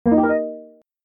positive-chord.mp3